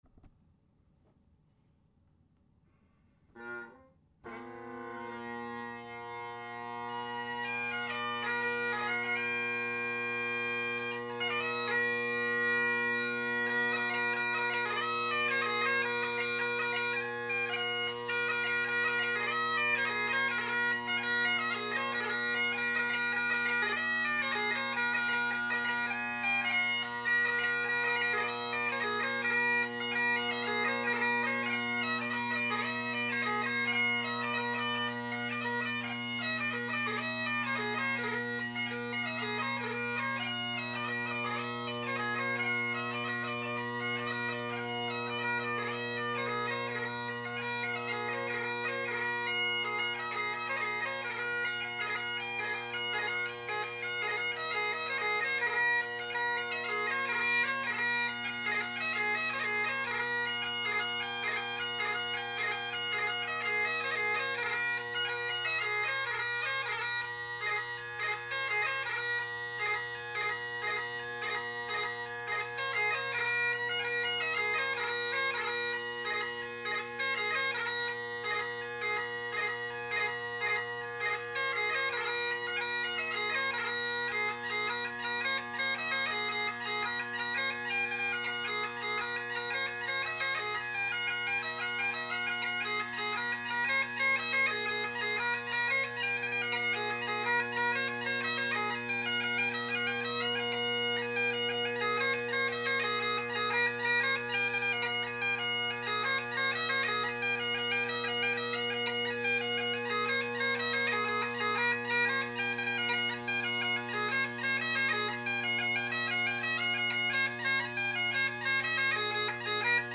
Great Highland Bagpipe Solo
1950’s Hendersons – Selbie drone reeds – Colin Kyo delrin chanter – heavily carved Apps G3 chanter reed
Be patient, each recording is unmodified from the recorder at 160 KB/s using mp3, there are a couple seconds before I strike in after I press record.